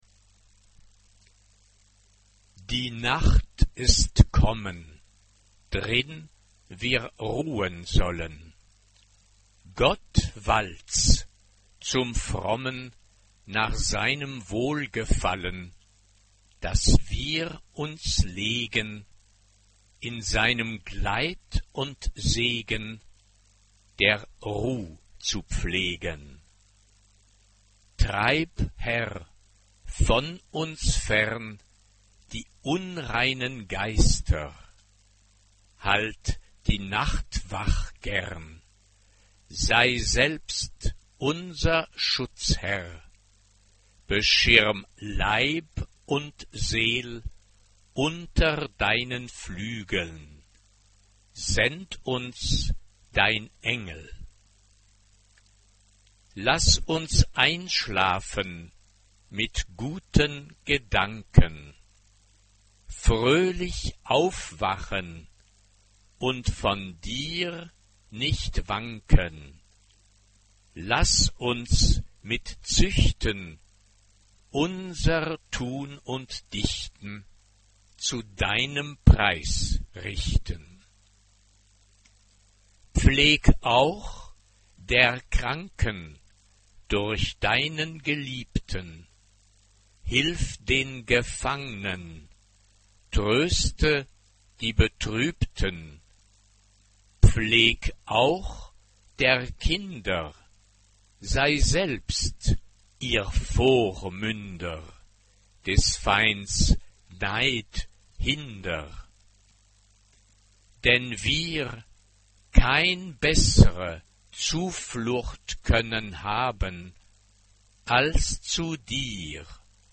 Genre-Stil-Form: geistlich ; Motette ; Barock Chorgattung: SSA (3 Frauenchor Stimmen )
Tonart(en): g-moll